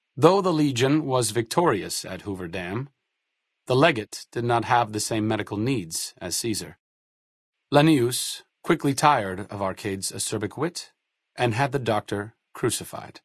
Category:Fallout: New Vegas endgame narrations Du kannst diese Datei nicht überschreiben. Dateiverwendung Die folgenden 2 Seiten verwenden diese Datei: Arcade Gannon Enden (Fallout: New Vegas) Metadaten Diese Datei enthält weitere Informationen, die in der Regel von der Digitalkamera oder dem verwendeten Scanner stammen.